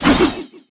metal_swipe_01.wav